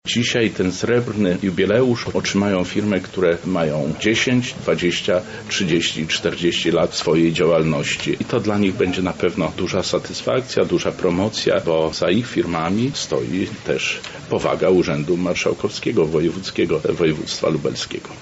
• mówi Zbigniew Wojciechowski, wicemarszałek województwa lubelskiego.